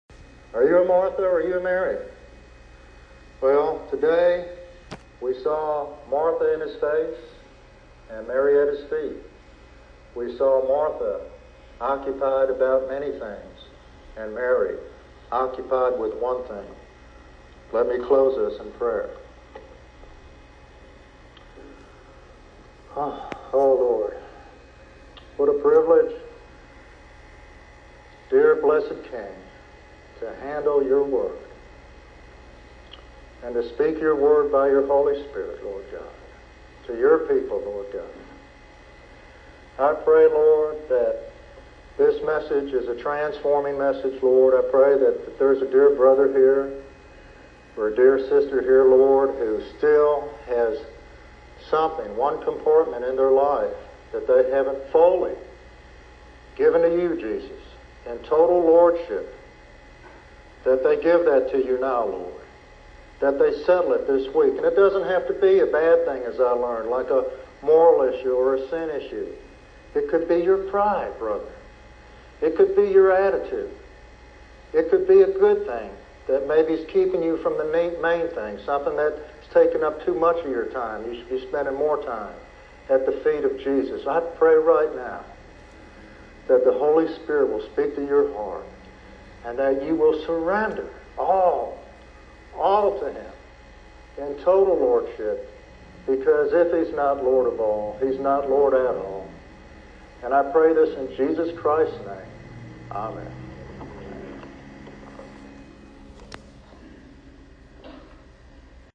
Through heartfelt prayer and biblical insight, he calls listeners to surrender completely to Jesus' lordship for true transformation.